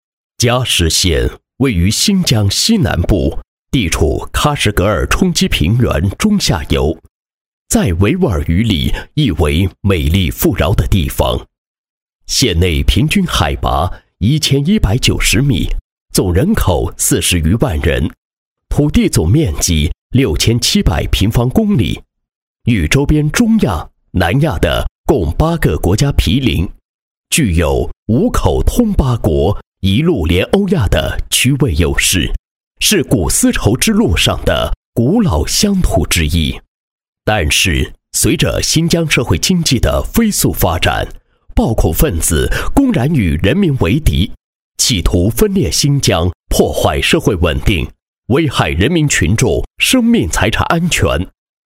公安男150号（沉稳大气）
大气沉稳、磁性，声线片中年。擅自专题汇报、党建、记录片、宣传片等题材。